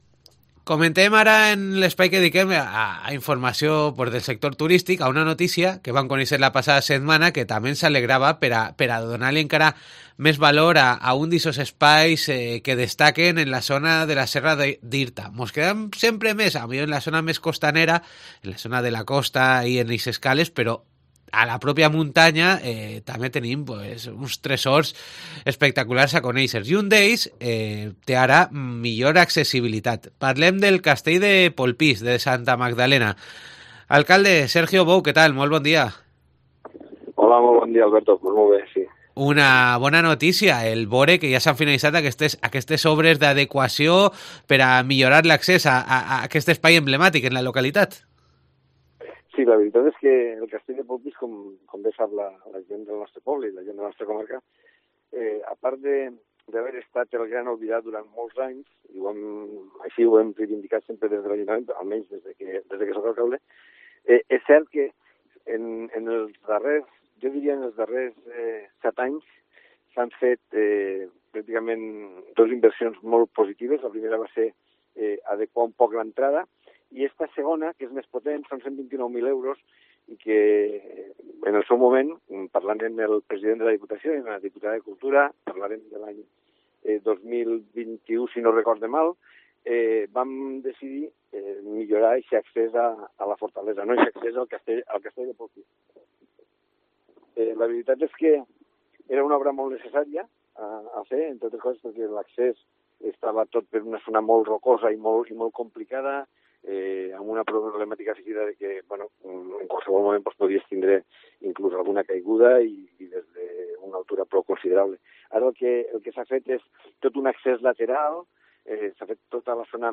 El alcalde de la localidad, Sergio Bou, resalta la lucha que lleva desde hace años para recuperar la parada del ferrocarril en la estación de la...